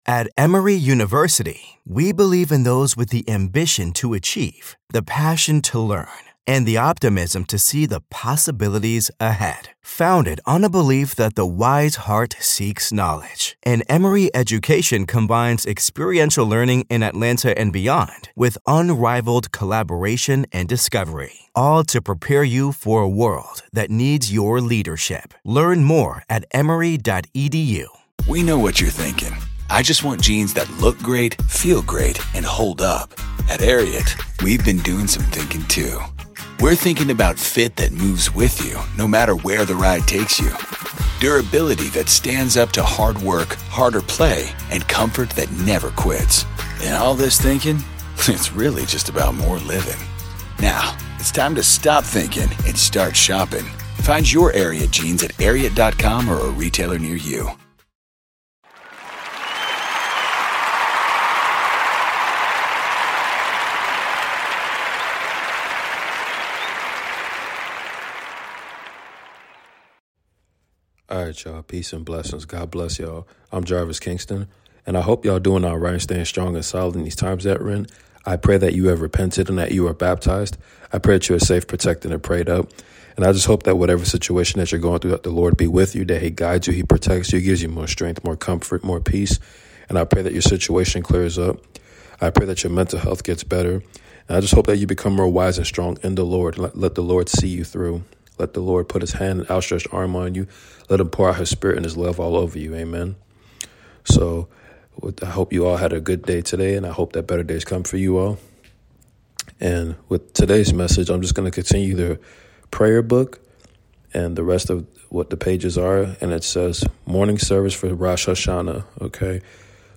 Continued Reading Of Prayerbook